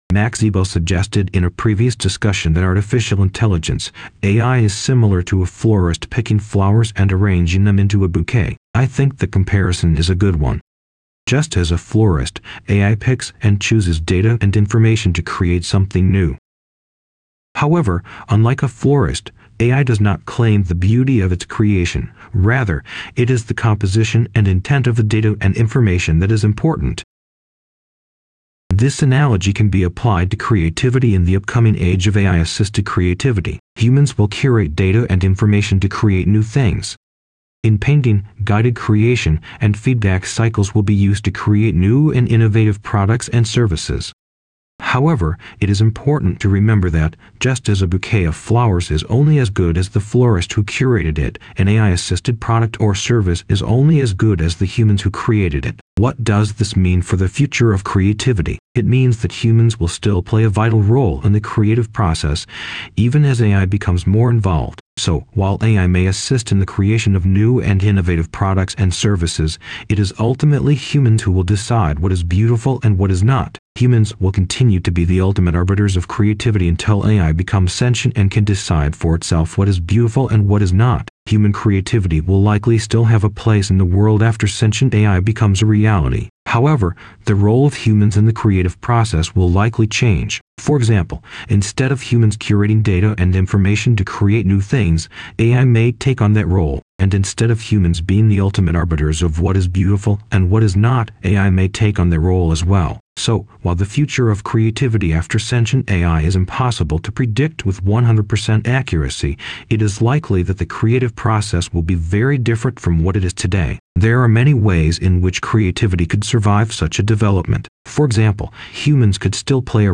LovoVoice.wav